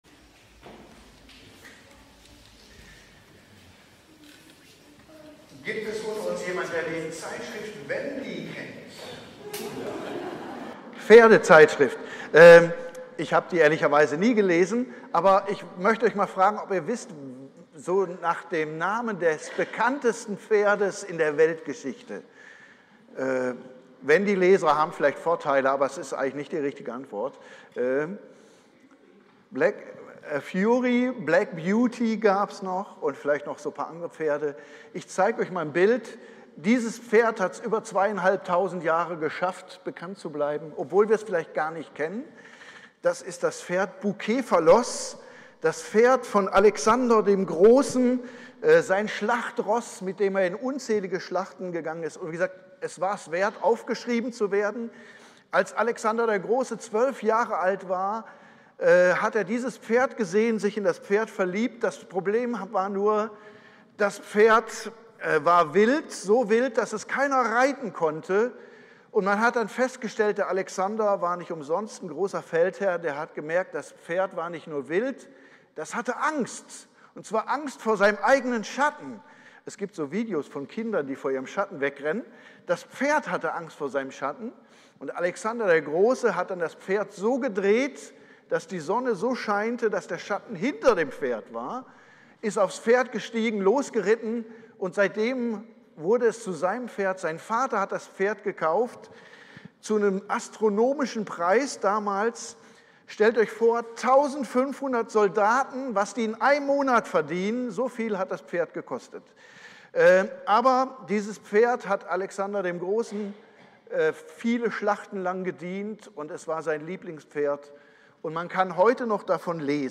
Predigt im Familien- und Lobpreisgottesdienst